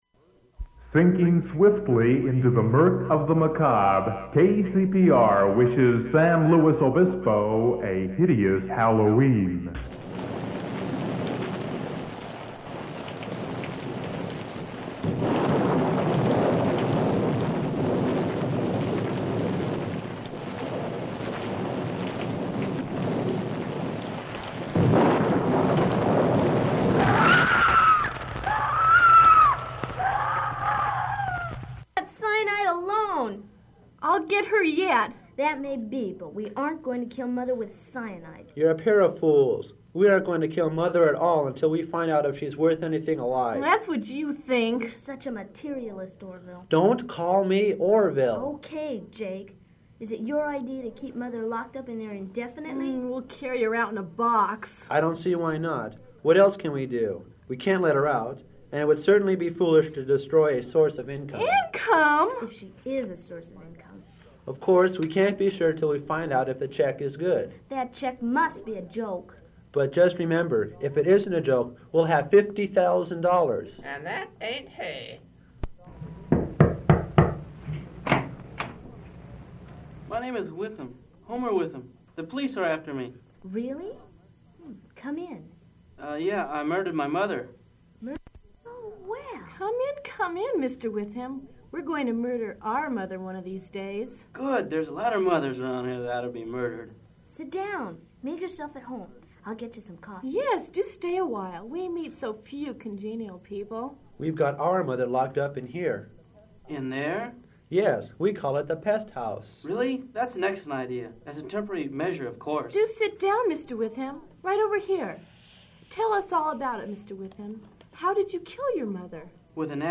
Download audio Metrics 26 views 18 downloads Citations: EndNote Zotero Mendeley Audio The Monster Halloween-themed radio play produced by KCPR.
Form of original Open reel audiotape